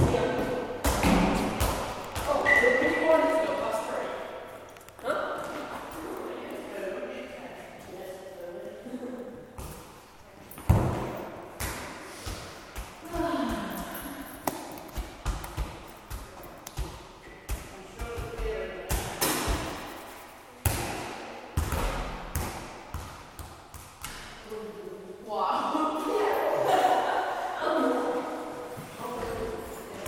Getting footballs out at the youth club